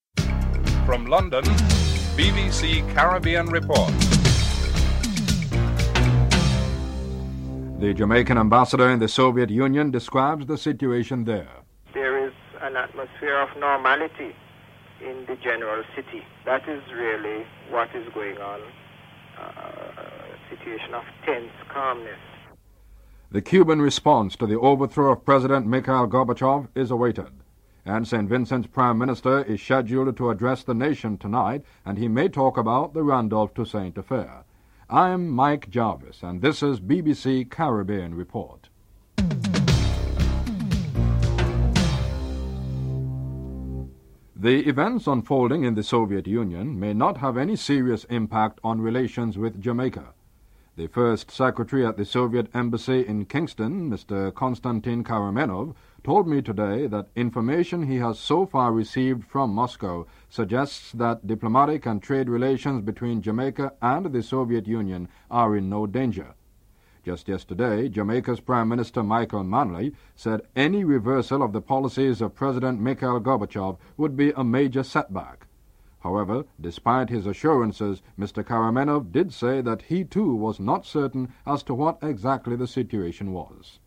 The British Broadcasting Corporation
1. Headlines (00:00-00:43)